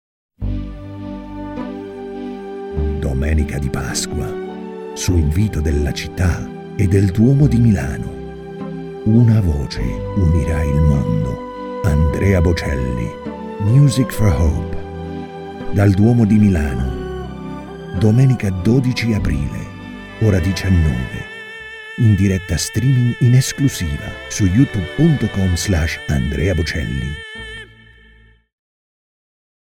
Male
Authoritative, Character, Conversational, Deep, Gravitas, Versatile, Warm